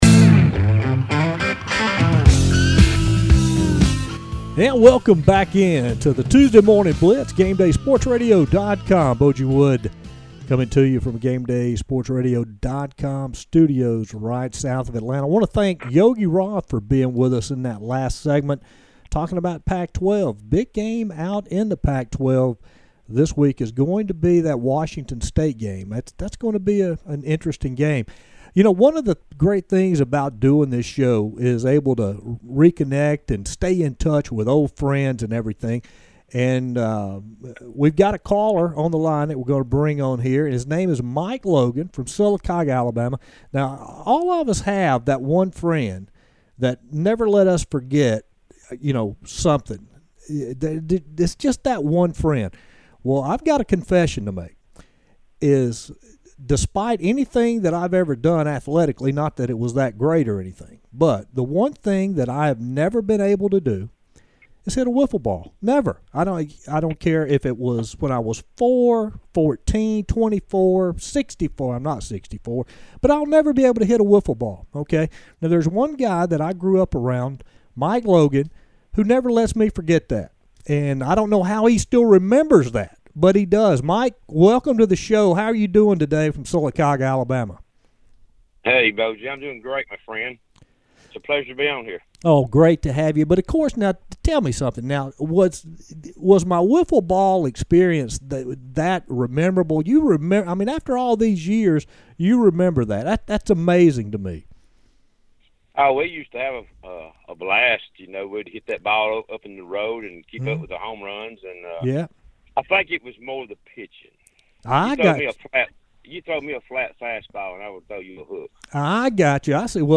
Morning Blitz Featured Caller